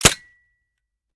weap_decho_fire_last_plr_mech_02.ogg